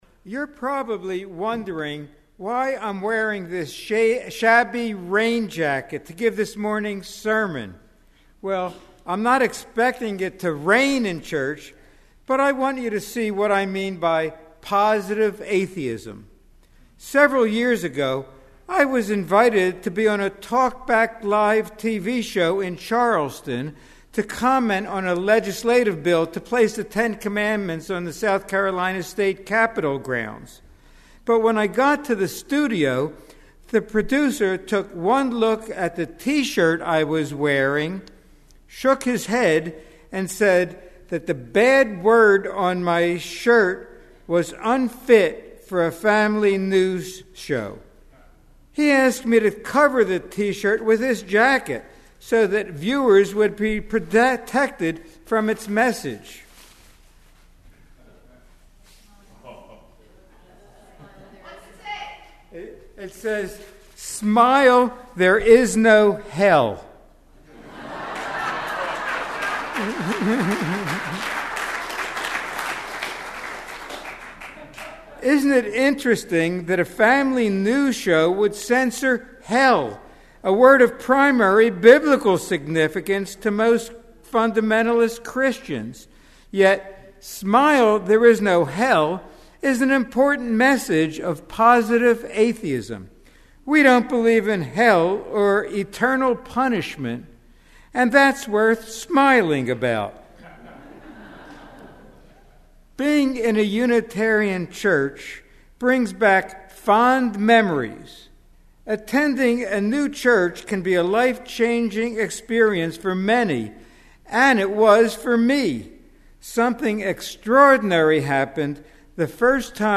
Sunday Services associate: